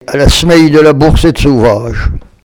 Localisation Olonne-sur-Mer
Catégorie Locution